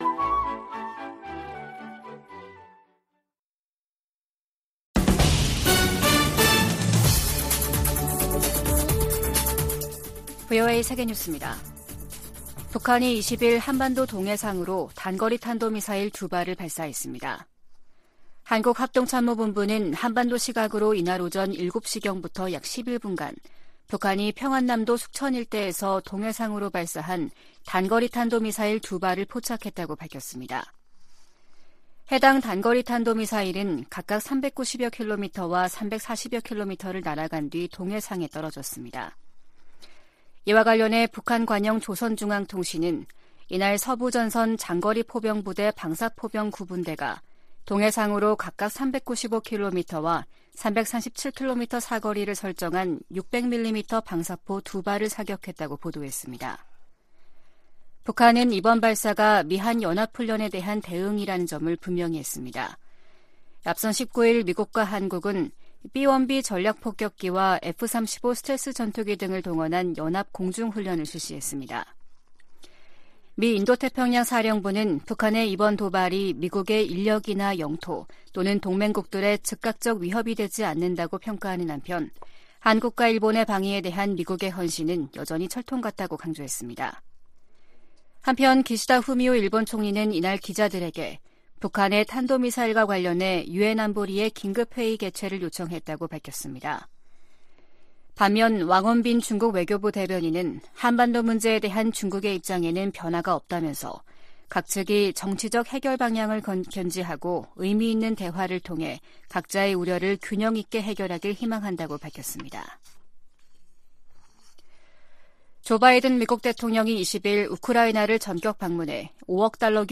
VOA 한국어 아침 뉴스 프로그램 '워싱턴 뉴스 광장', 2023년 2월 21일 방송입니다. 북한이 '화성-15형' 대륙간탄도미사일(ICBM)을 쏜 지 이틀 만에 평안남도 숙천 일대에서 동해상으로 초대형 방사포를 발사했습니다. 미국과 한국, 일본 외교장관이 긴급 회동을 갖고 북한의 대륙간탄도미사일(ICBM) 발사를 규탄하면서 국제사회의 효과적인 대북제재 시행을 촉구했습니다. 한국은 북한의 대륙간탄도미사일 발사 등에 대해 추가 독자 제재를 단행했습니다.